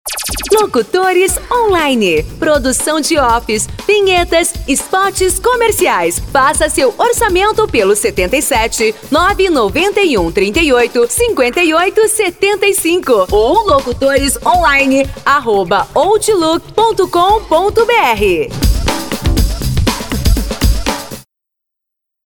EstiloLocutores